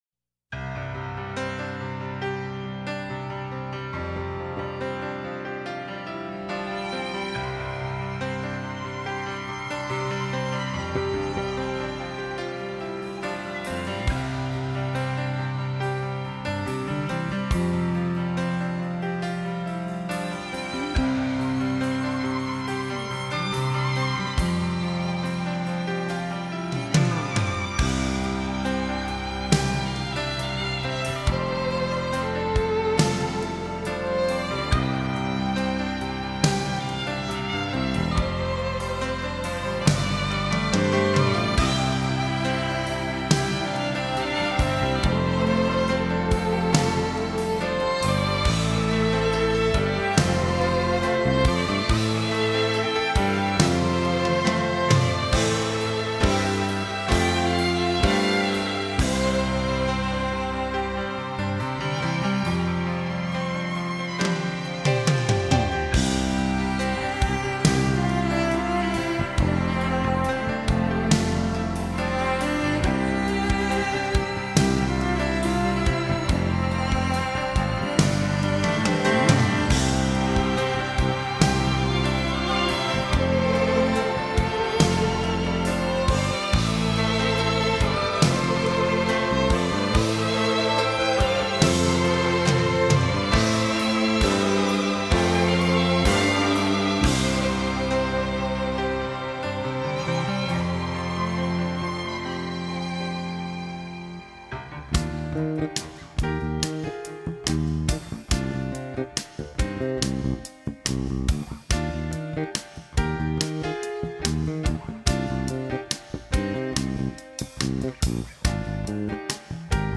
滑动的琴键有如流水潺潺，一个无形的奇幻梦境，在木琴轻敲声中，更显浪漫。